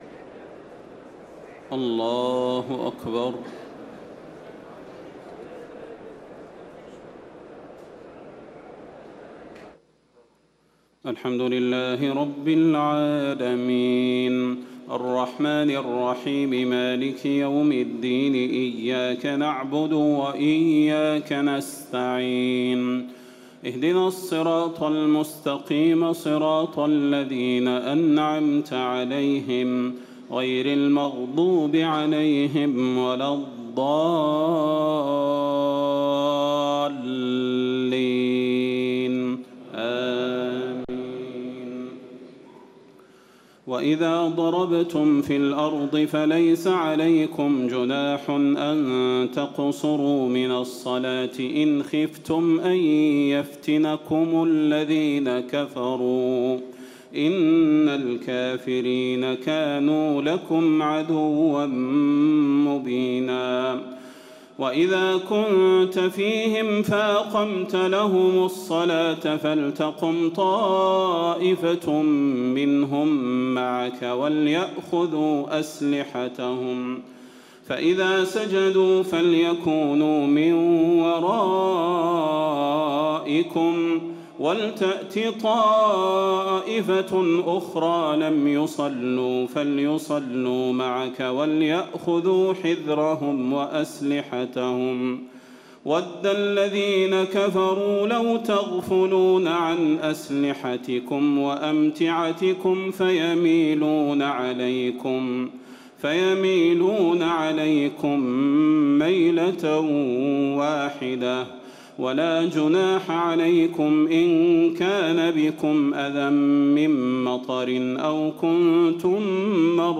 تهجد ليلة 25 رمضان 1438هـ من سورة النساء (101-147) Tahajjud 25 st night Ramadan 1438H from Surah An-Nisaa > تراويح الحرم النبوي عام 1438 🕌 > التراويح - تلاوات الحرمين